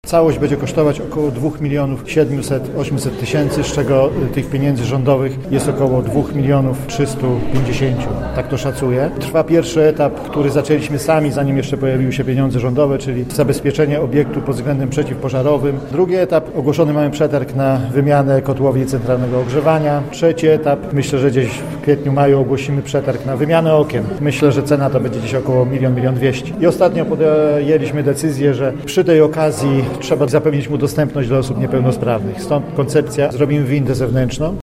Zbigniew Szumski, starosta świebodziński, mówi, że bez wsparcia finansowego ze strony rządu nie udałoby się zrealizować tak dużej inwestycji.